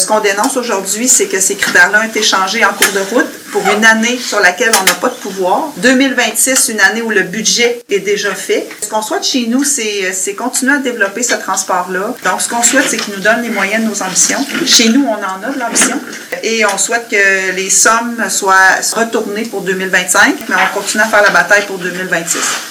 Lors de la séance du conseil de mercredi, la préfète de la MRC, Geneviève Dubois, y est allée d’une sortie.